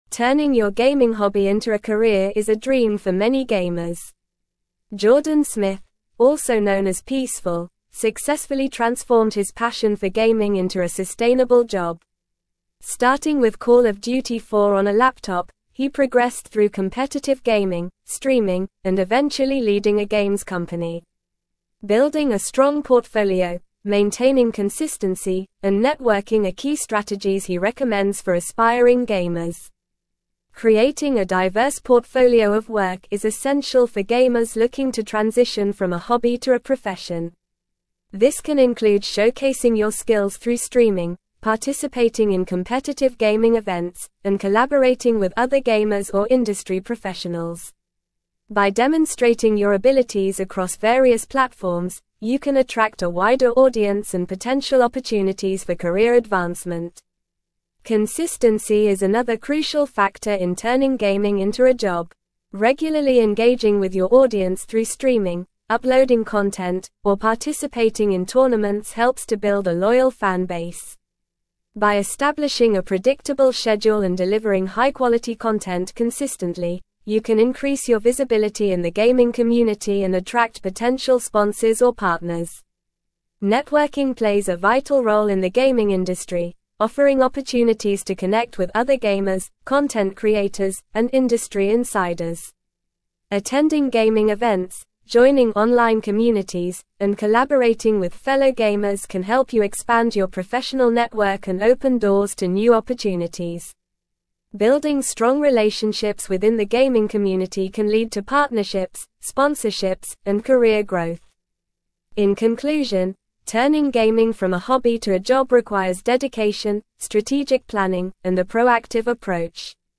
Normal
English-Newsroom-Advanced-NORMAL-Reading-Turning-Gaming-Passion-into-Career-Success-Key-Strategies.mp3